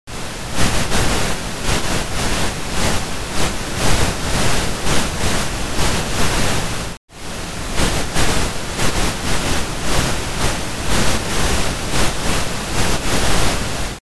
Since the goal here is to produce a signal that varies only in volume, I instead substitute a sample of pink noise.
6.4—Another type of inscription that can be educed using this technique is the logogram, or the record made on the logograph of William Henry Barlow, an instrument that recorded the pressure of air (not sound vibrations!) while someone was speaking.